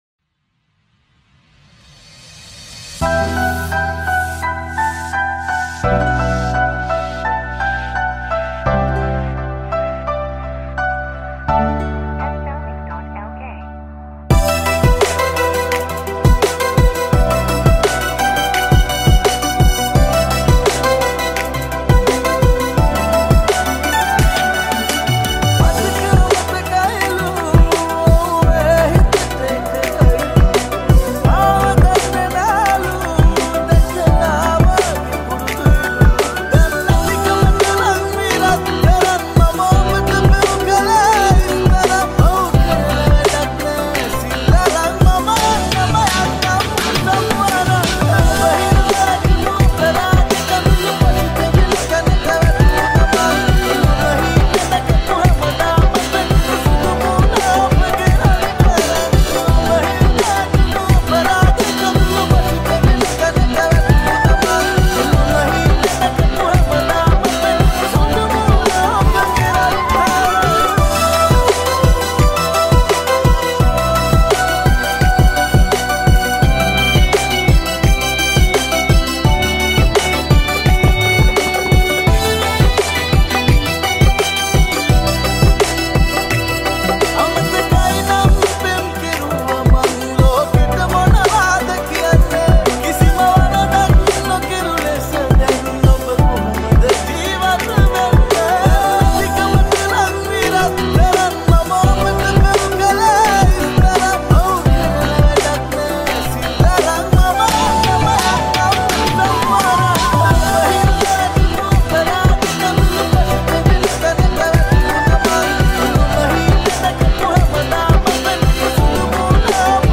Remix CooL Hip Style.